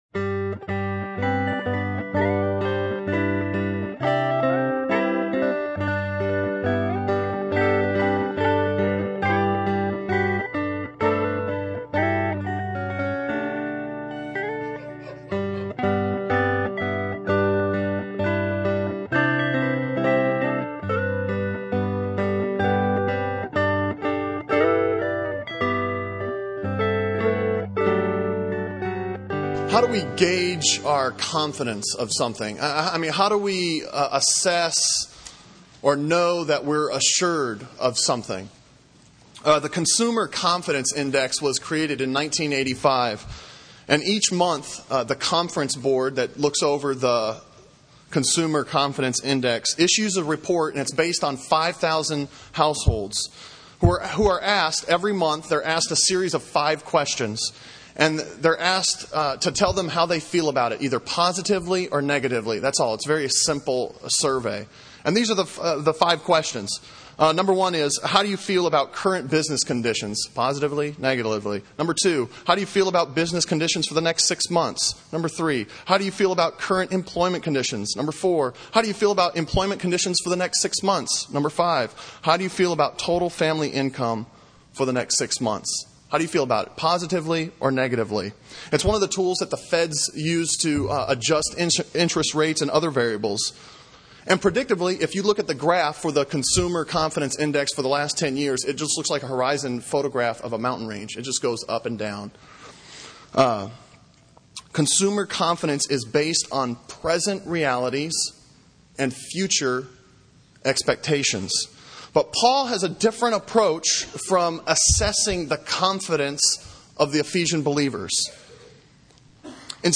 Sermon on Ephesians 1:11-14 from September 9